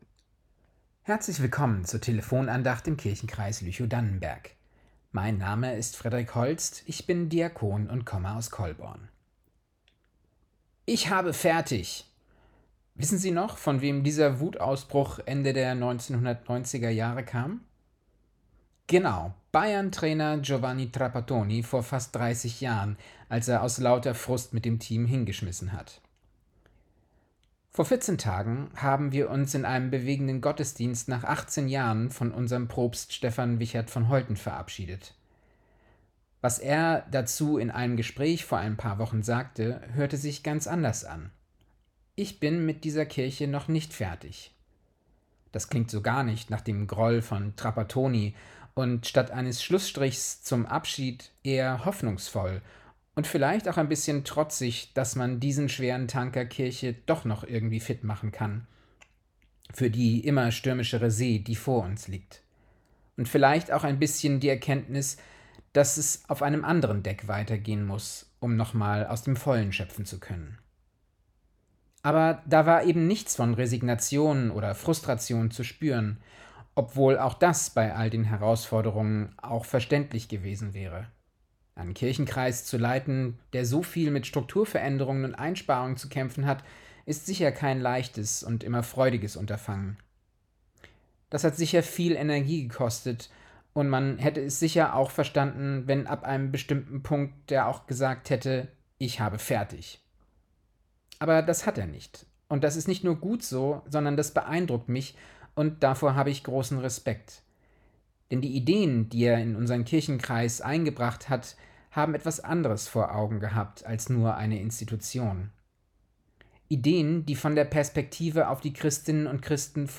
~ Telefon-Andachten des ev.-luth. Kirchenkreises Lüchow-Dannenberg Podcast